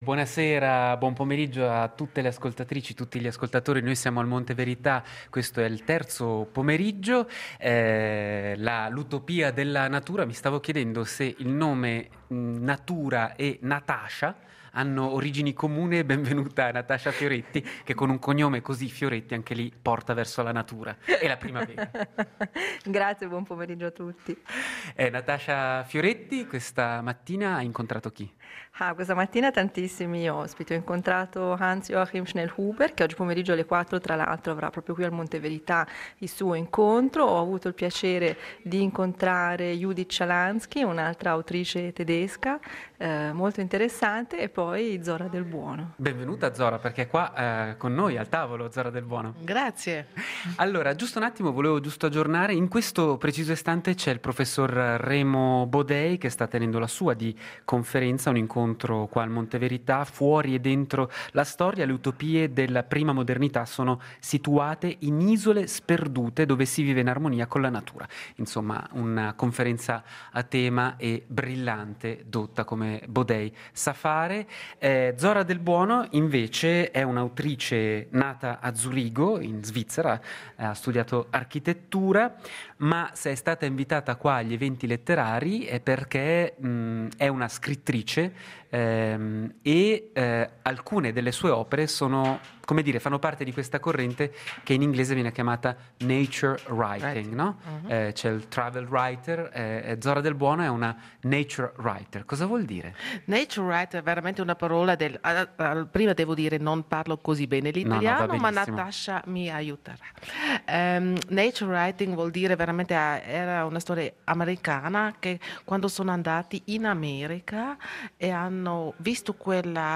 Attualità culturale Eventi Letterari Monte Verità Ascona "Utopia della natura" 24.03.2018 39 min Facebook Contenuto audio Disponibile su Scarica In Passatempo vi parliamo dell’edizione 2018 degli Eventi Letterari con collegamenti in diretta dal Monte Verità di Ascona, dove da giorni si sta parlando di utopia della Natura.